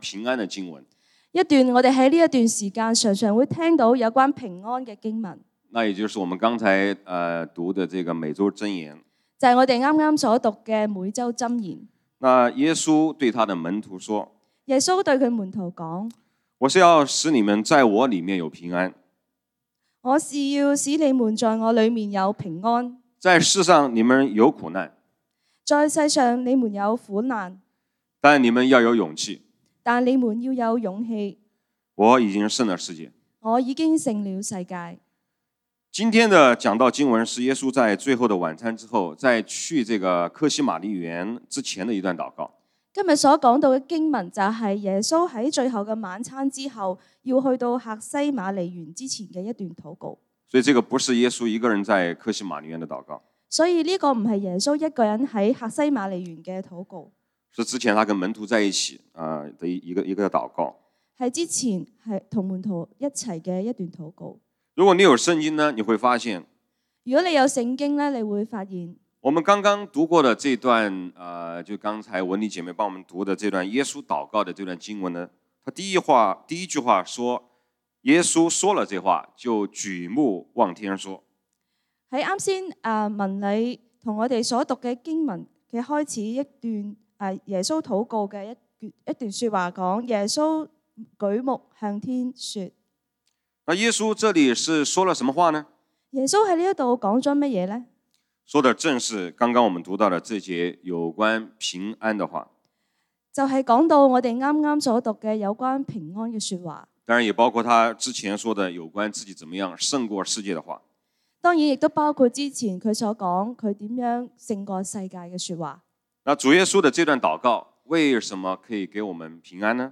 講道經文：《約翰福音》John 17:1-11 本週箴言：《約翰福音》John 16:33 「耶穌說：『你們在我裡面有平安。